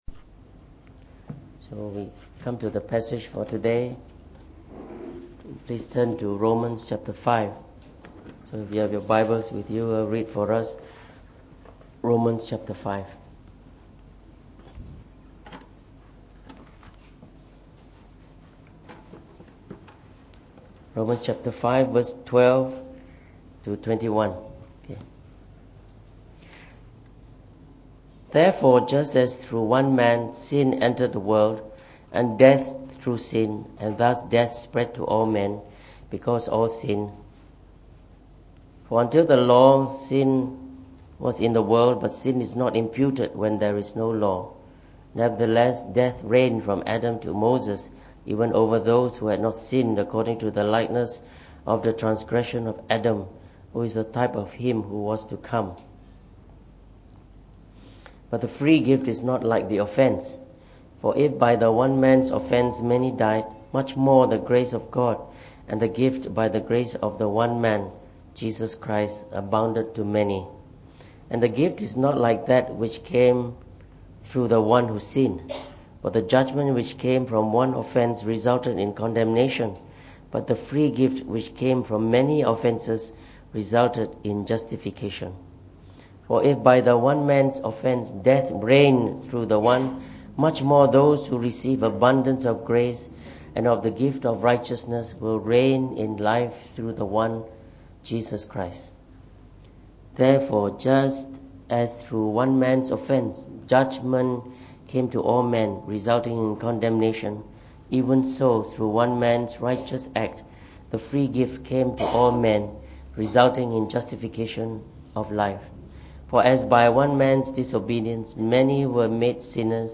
Preached on the 1st of July 2018. From our series on the Book of Romans delivered in the Morning Service